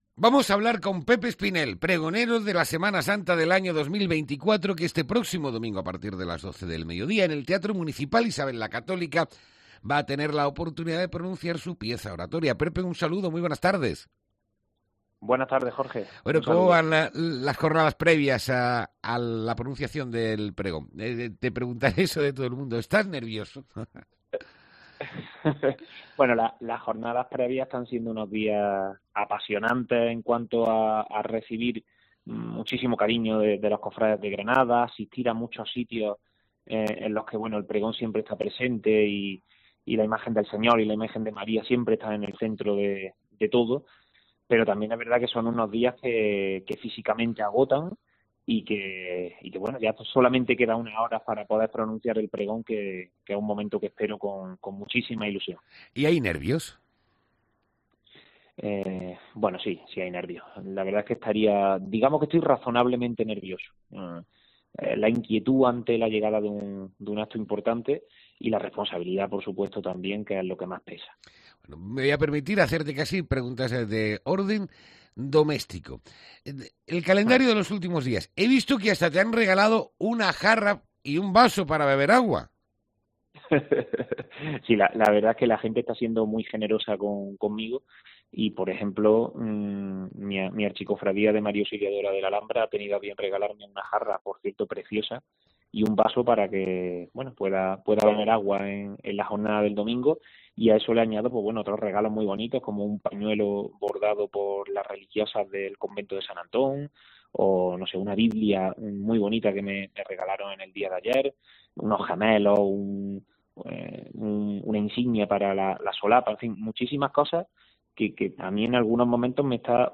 ENTREVISTA|| El Pregonero de la Semana Santa se acompañará de un piano